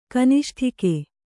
♪ kaniṣṭhike